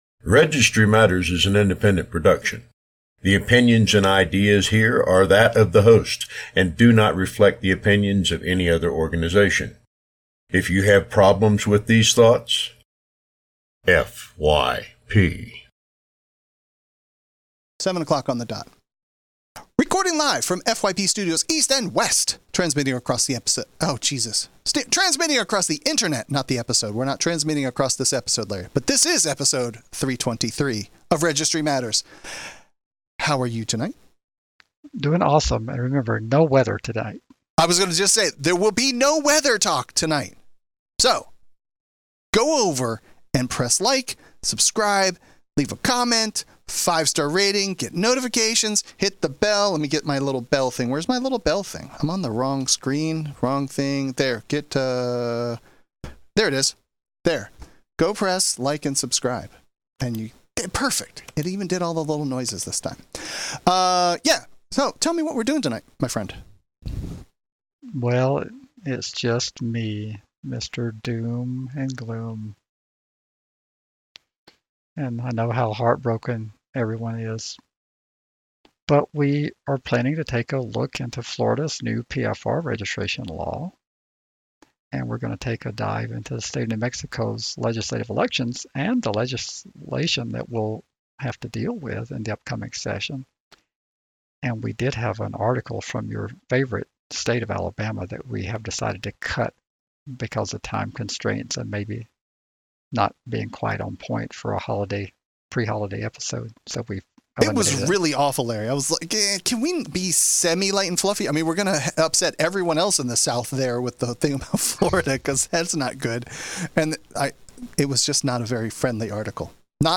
We break down the significant changes, discuss their implications for registrants, and examine the broader impact on civil rights and compliance. Join us for an in-depth conversation about the future of PFR laws in Florida and what these updates mean for everyone involved....